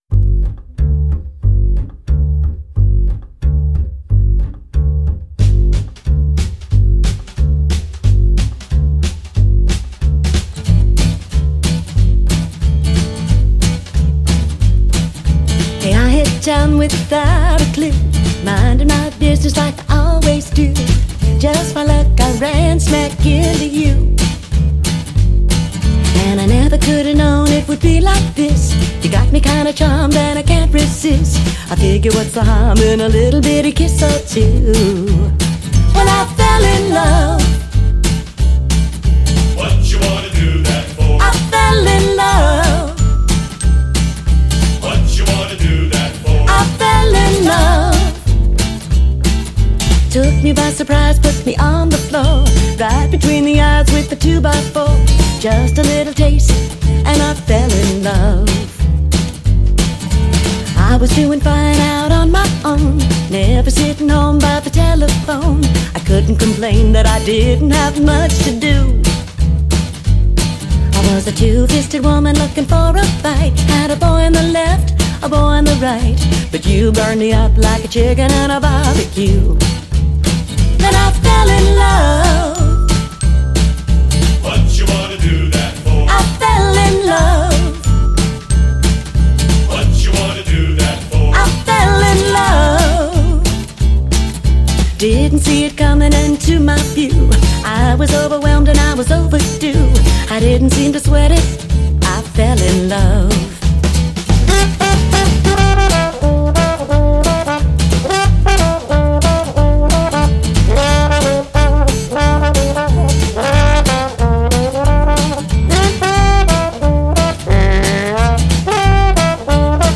The Joyful Side of Jazz,
Soul and Gospel Music!